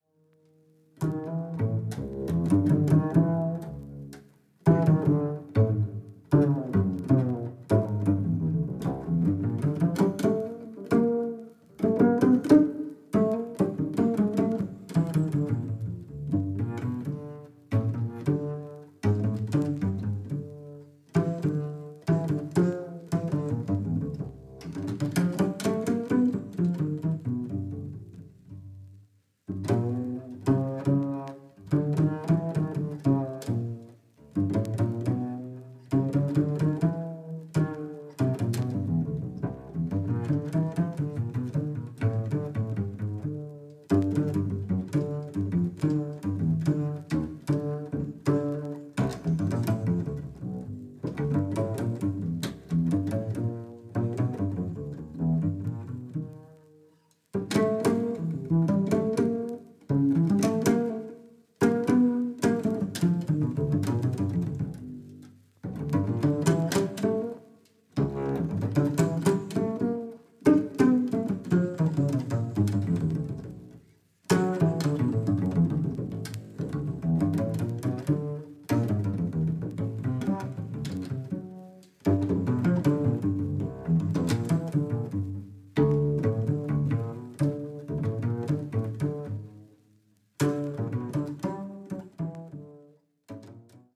Double Bass